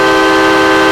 5 chime horn 5a.ogg